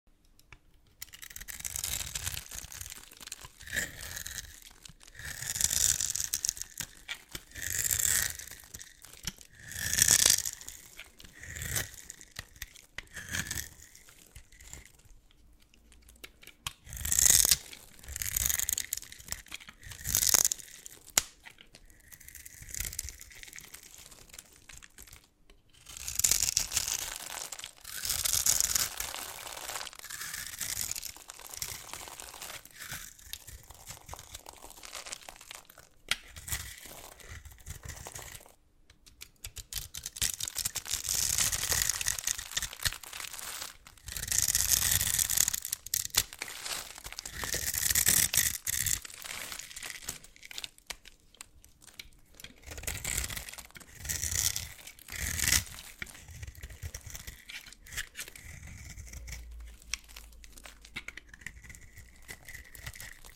Cutting soap cubes